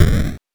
8 bits Elements / explosion
explosion_20.wav